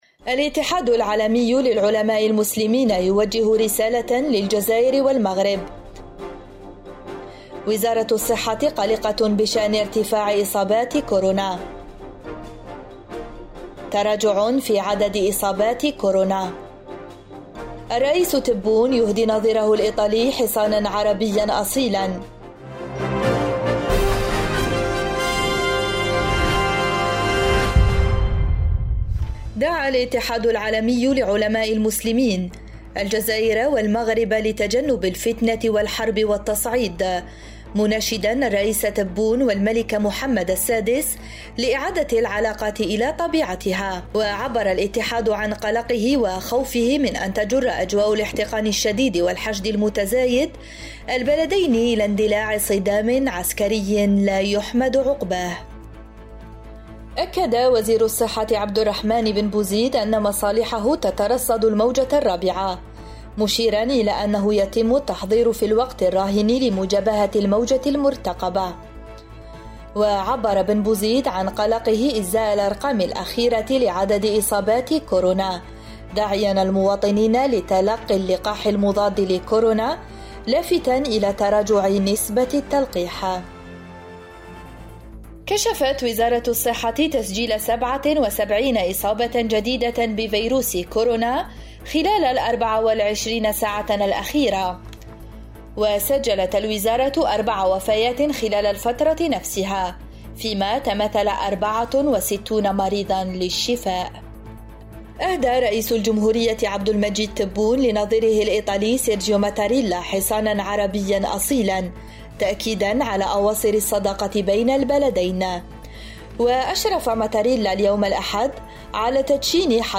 النشرة الرقميةفي دقيقتين